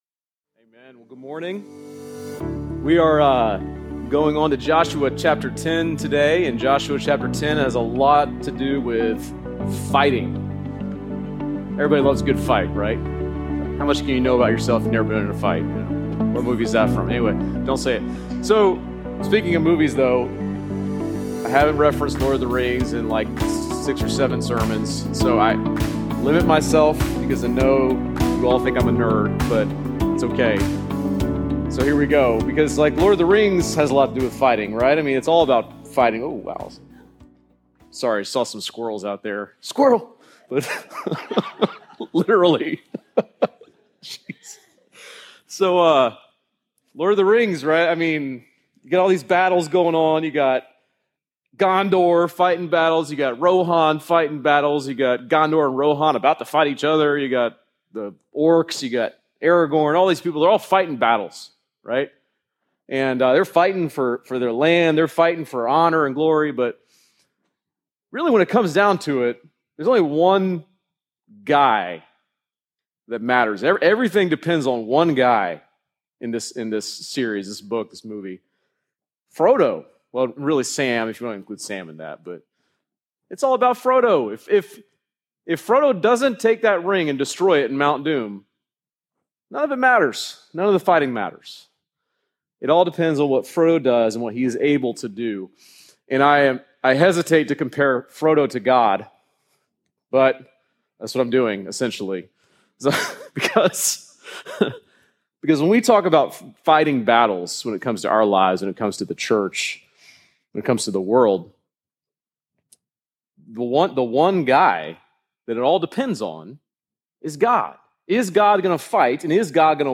Join us in this 13 week sermon series through Joshua and discover what it looks like to find our strength and courage in the Lord!